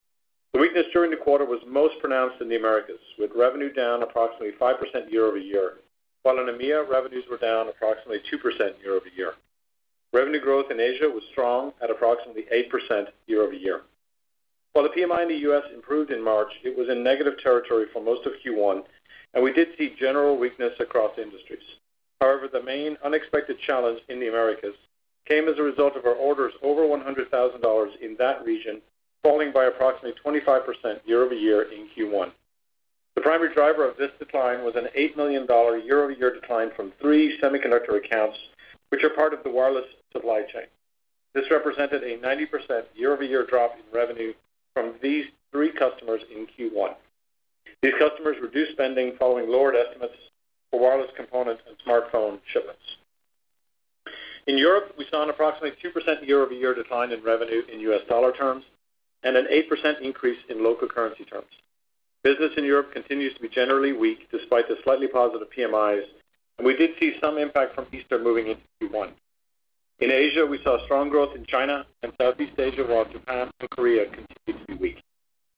During an investor call on April 4, 2016